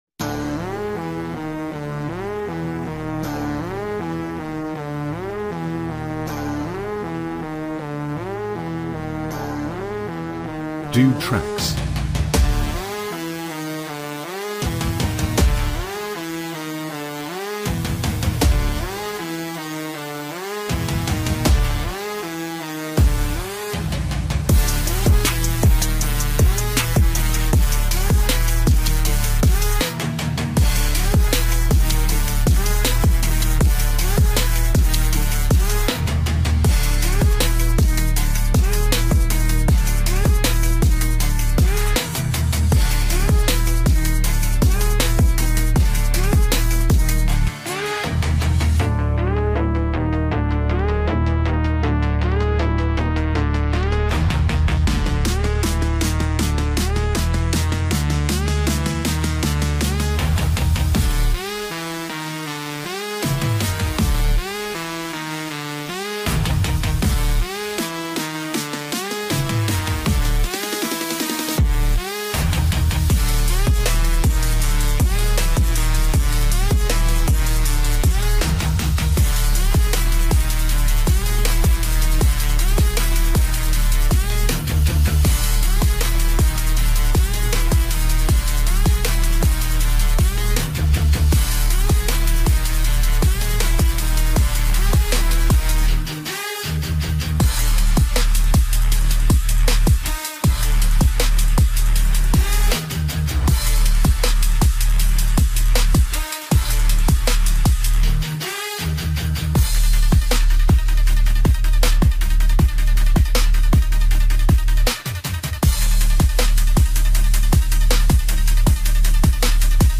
Royalty-Free Hip Hop Beat
epic no copyright music beat